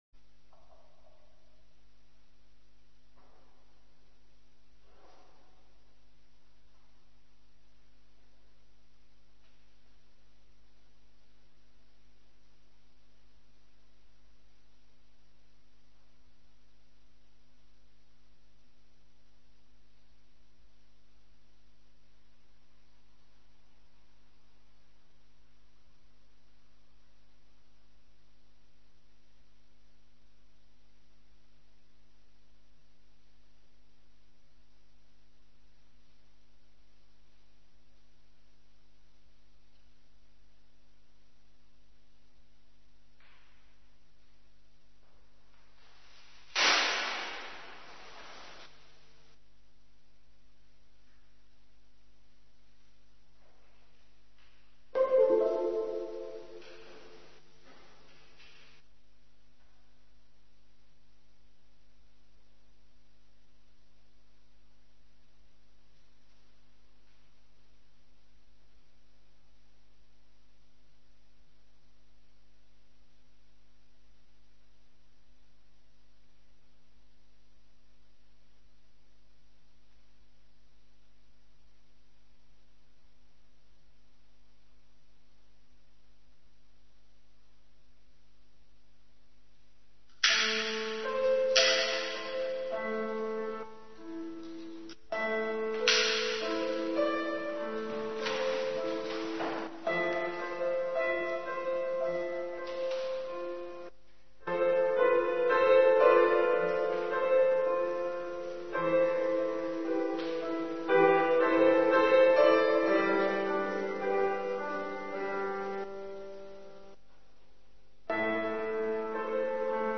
2020-06-17 Film 2e online vesper na pinksteren
Bij het opnemen van de vesper via Facebook live ging het op een gegeven moment niet goed. Geluid en beeld liepen uit elkaar (geluid ging heel snel).